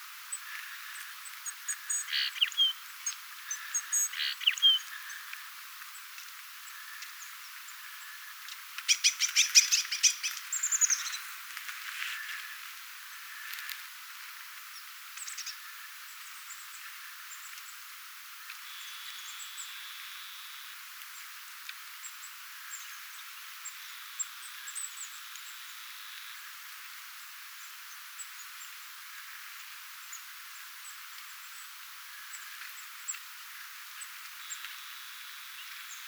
Hyvin vilkkaasti k�viv�t tiaiset lintulaudalla.
vahan_tiaisten_aantelya.mp3